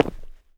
mining sounds
ROCK.6.wav